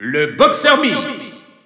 Announcer pronouncing Mii Brawler's name with masculine pronouns in French PAL in victory screen.
Mii_Brawler_M_French_EU_Alt_Announcer_SSBU.wav